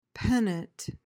PRONUNCIATION: (PEN-uhnt) MEANING: noun: 1.